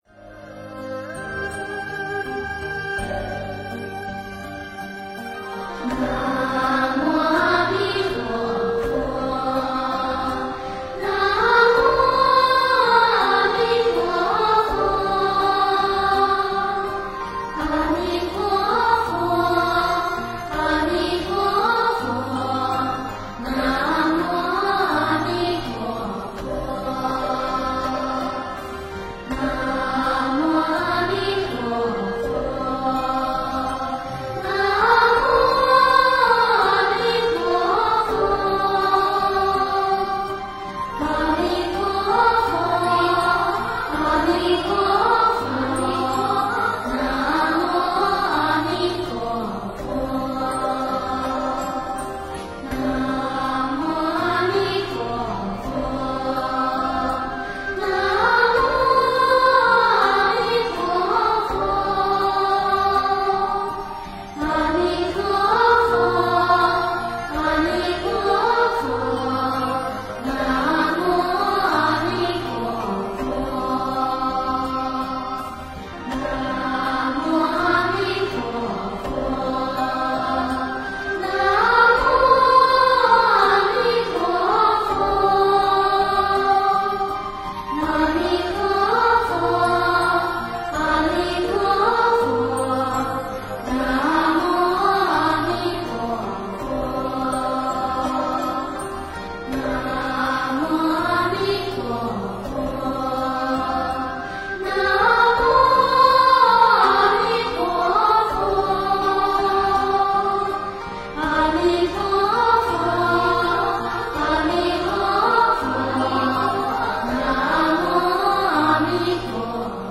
南无阿弥陀佛(童音缓慢版)--佛教音乐